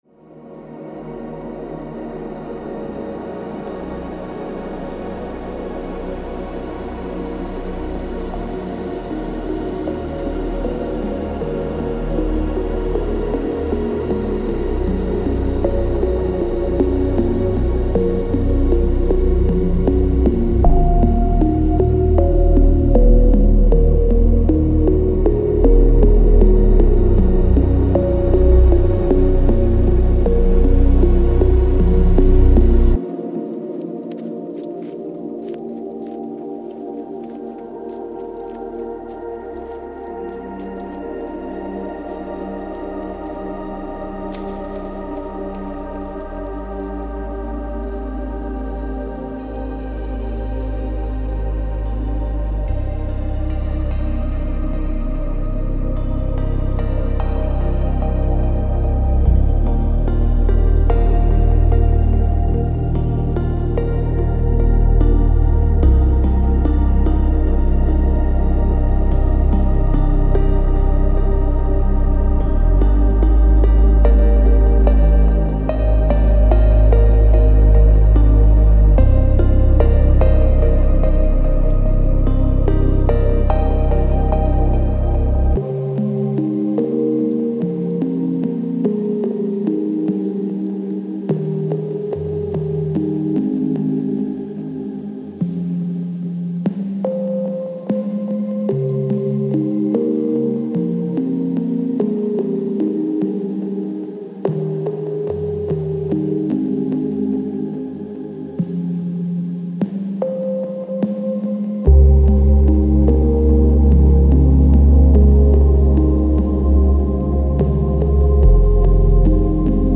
Genre:Ambient
デモサウンドはコチラ↓